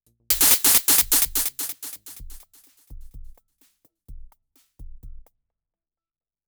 Random horrible repeating noise when changing patterns remotely - Digitone/Digitone Keys - Elektronauts
To test this and trying to make it happen I put Digi to very low volume (to protect my hearing and my audio equipment) and recorded it audio.
But in my test with very low general Digi volume I could hear this crash noise delaying and then fading and soon (about two bars) this “delay” is gone and pattern plays normally.
After this “noise delay” there’s a normal drum pattern which is barely hearable.